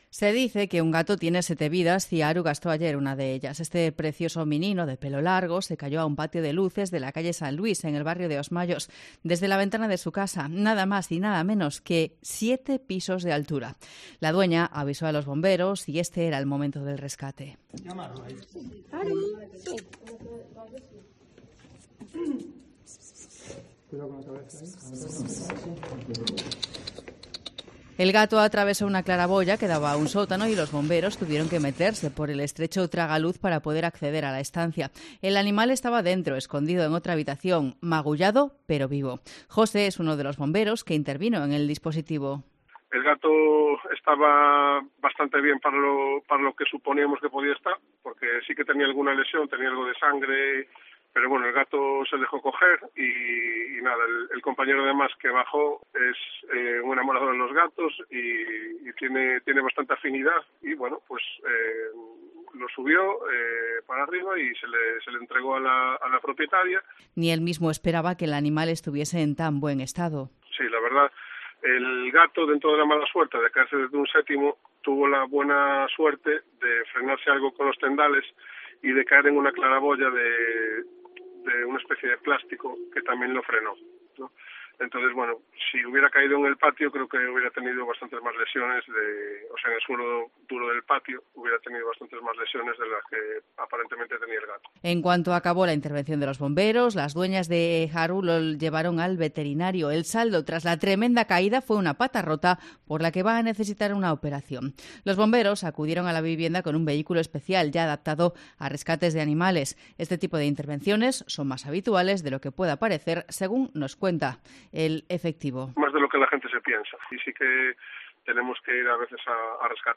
Hablamos con uno de los efectivos que intervino en el operativo